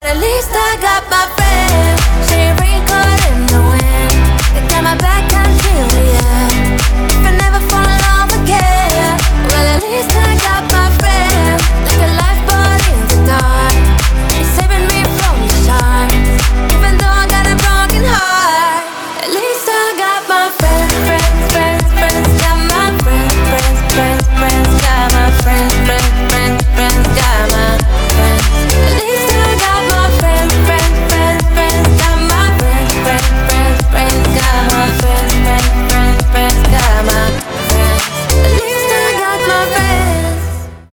танцевальные
dance pop , ремиксы